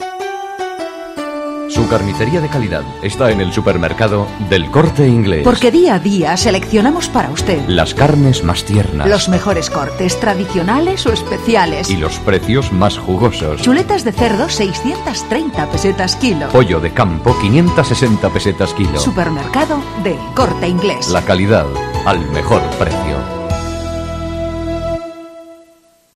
Anuncios sobre la fiesta de la moda, servicios de El Corte Inglés, supermercados, ropa de baño... narrados con voces sugerentes y aún en pesetas, que nos recuerdan cómo la herencia del pasado puede servirnos para alumbrar nuevas estrategias en el futuro.
Anuncio carnicería El Corte Inglés